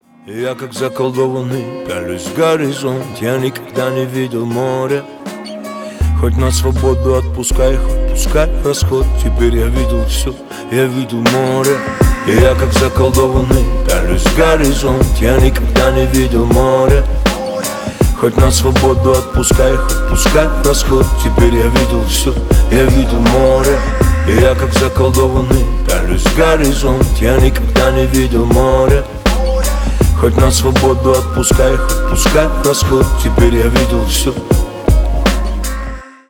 Рэп и Хип Хоп
кавер